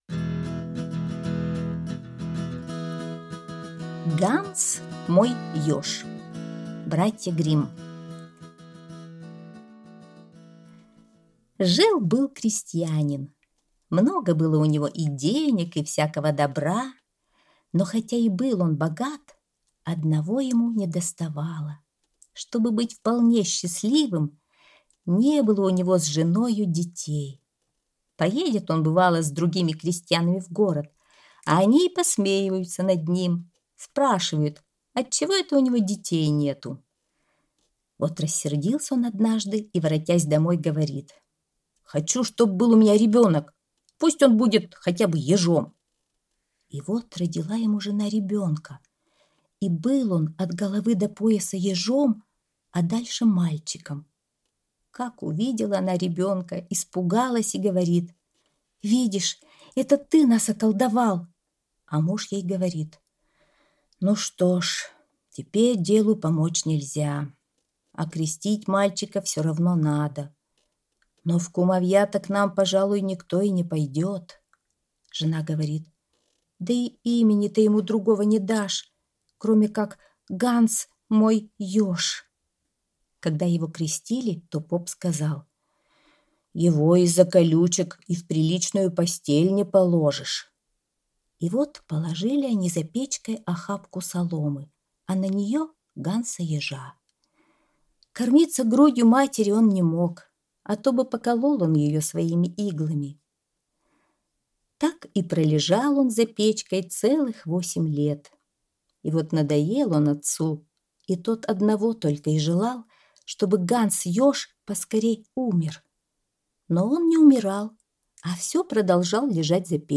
Ганс - мой Еж - аудиосказка Братьев Гримм - слушать онлайн